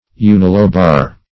Unilobar \U`ni*lo"bar\, a.